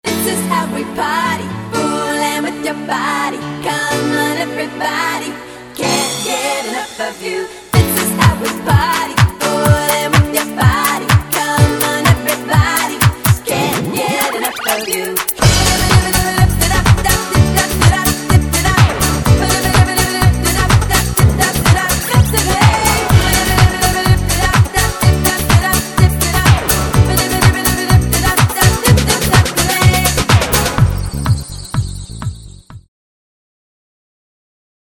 VOCAL DEMO
dance